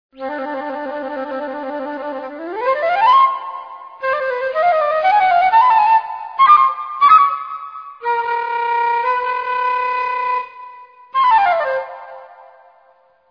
Flauto
Agile, brillante,
ma pastoso.
flauto.mp3